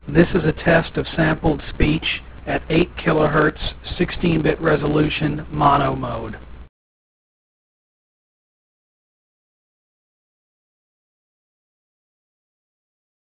If you play the below speech file you will see that the quality of this simple compression yields very impressive results. It is clear what the speaker is saying and also who is speaking.
Residual Excited Speech